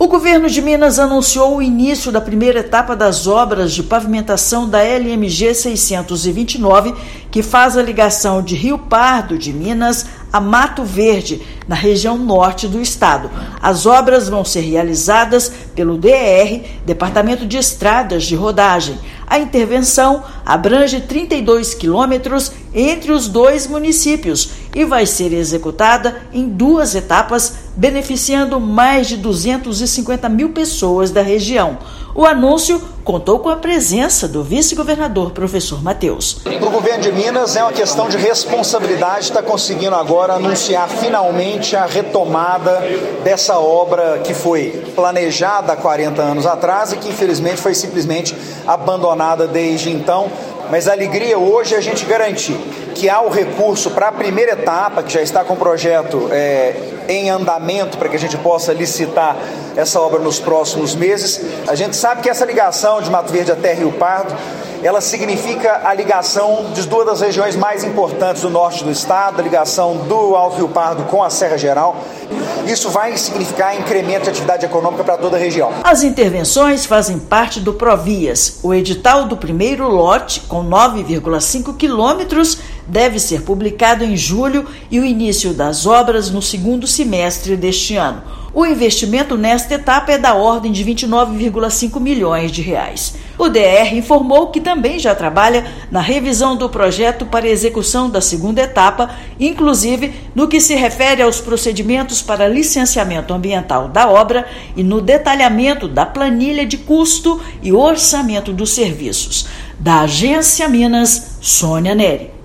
Esperada há 40 anos, intervenção em trecho de 32 quilômetros vai beneficiar população de 250 mil pessoas; DER-MG detalha orçamento para lançar edital. Ouça matéria de rádio.